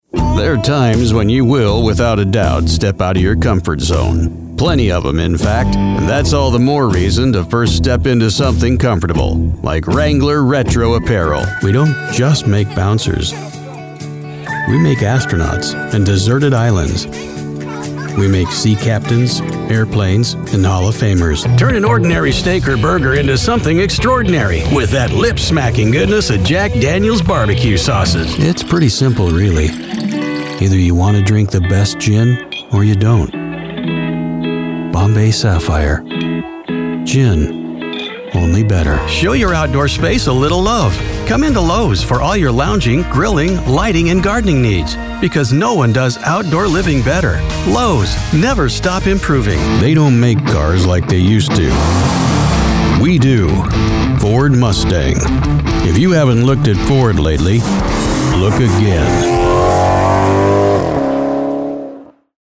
• Clean, broadcast‑ready audio from a professional studio
Just a real voice with real grit.
Commercial Voice Over Demos
Grit and Gravitas